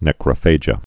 (nĕkrə-fājə)